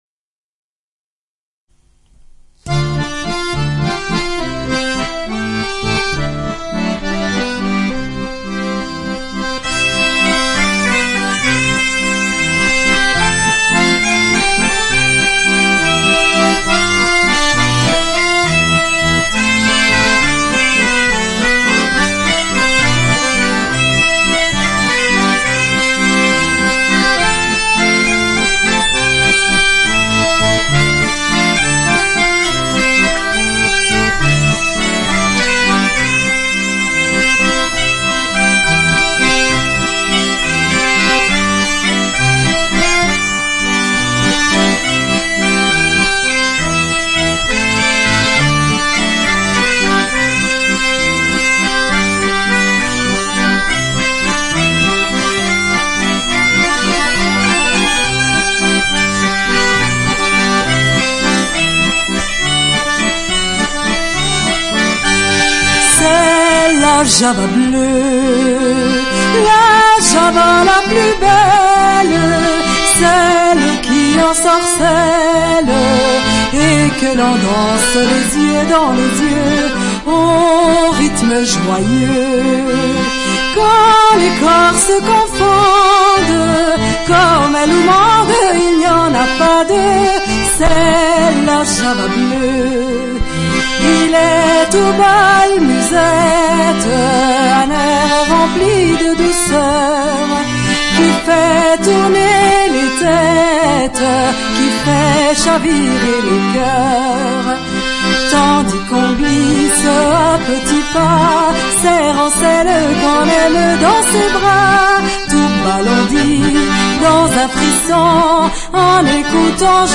Registrazioni live: